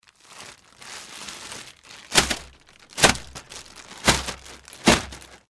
mf_SE-4912-plastic_bag.mp3